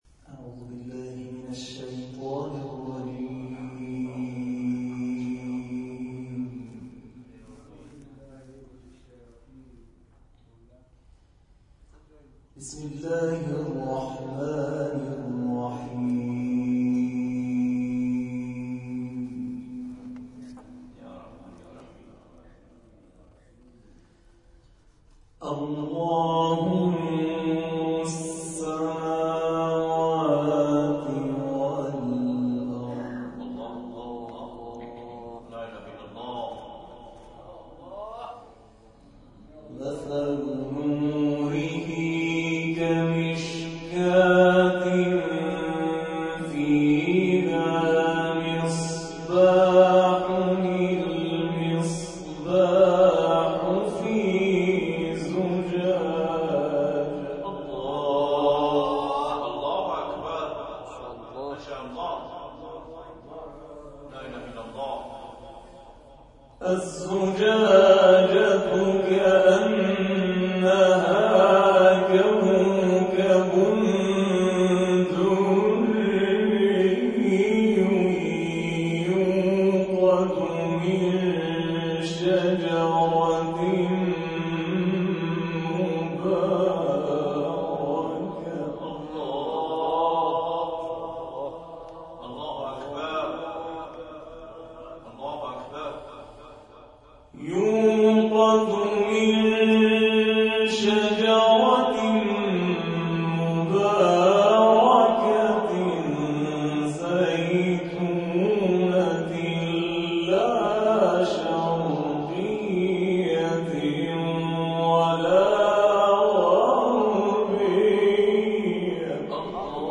جلسه قرآنی با قدمت 50 سال در سرچشمه